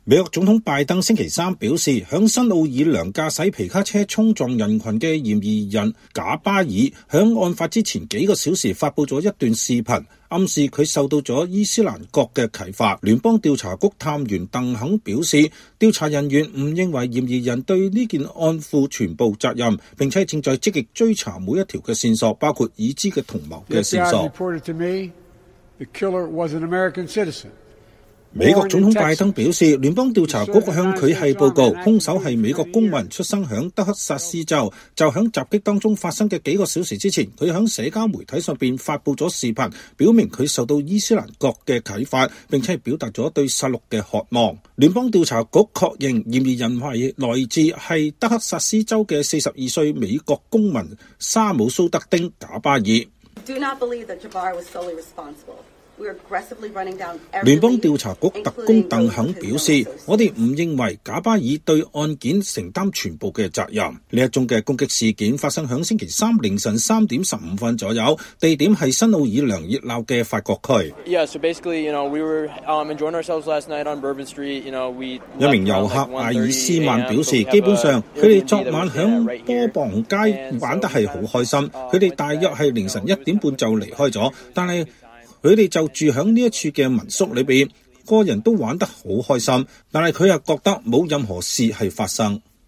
美國總統拜登新奧爾良卡車相撞事件在戴維營發表講話。